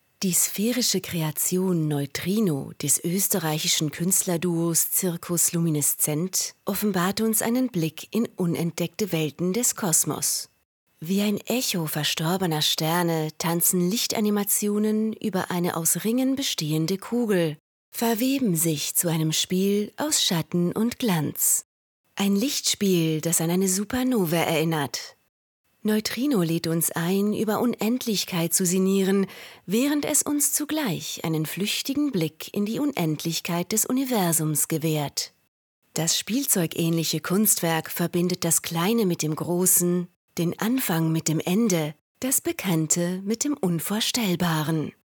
Audiodeskription